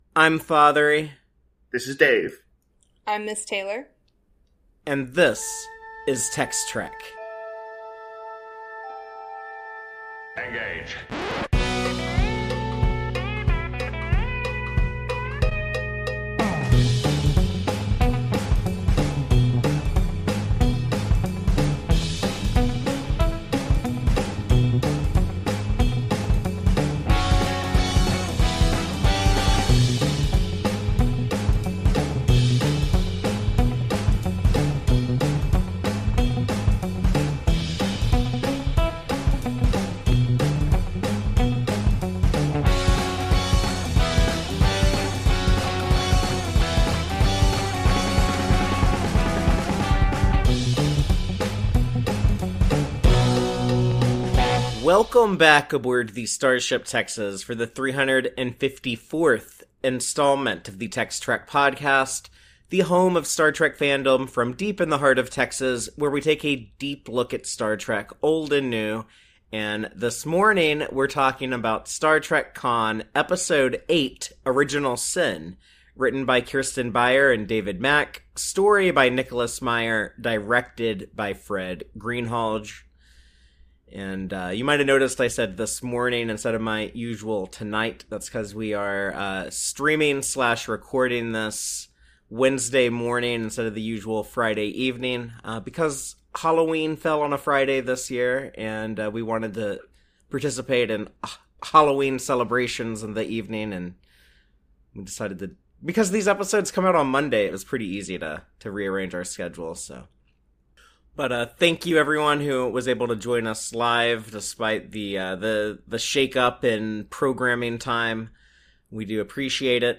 TEX-TREK Mission 355: STAR TREK: KHAN Finale, "Eternity's Face" Deep Dive Discussion